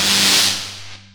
double time pitch shift
Electric zap.wav